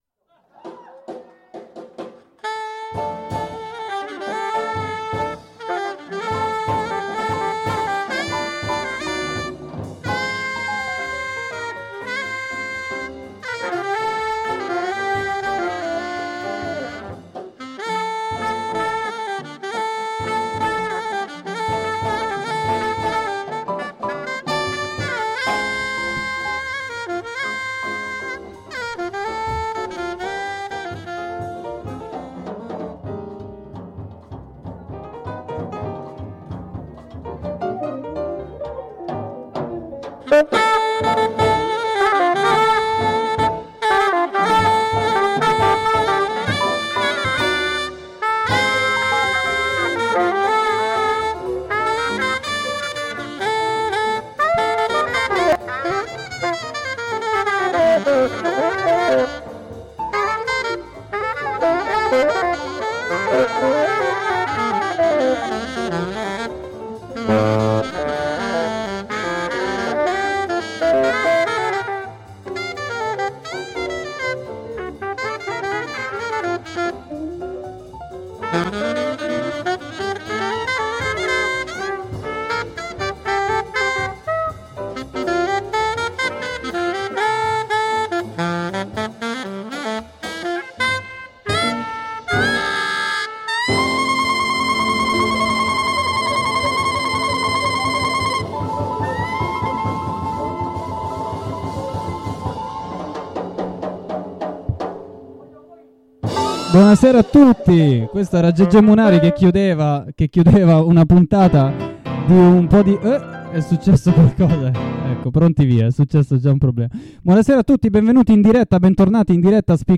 Intervista web a Antonio Farao
In diretta web!